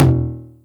909 Tom  RM.wav